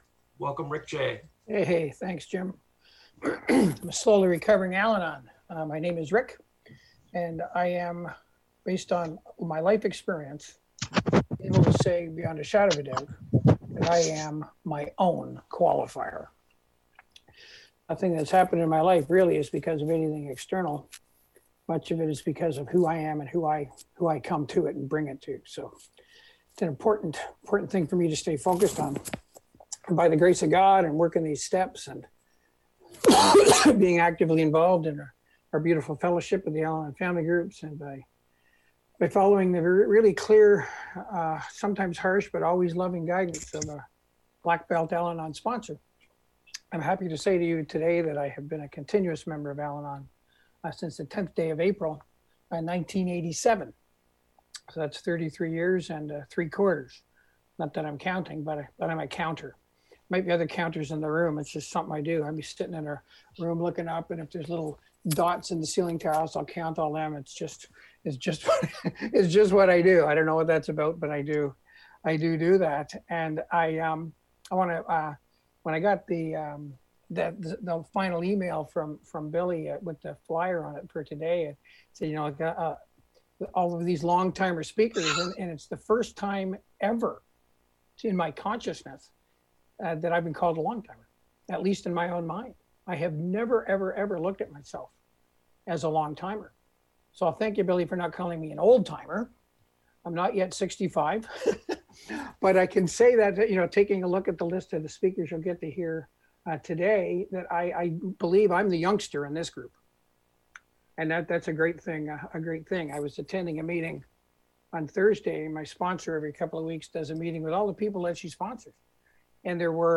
AWB Sunday Special Speaker Meeting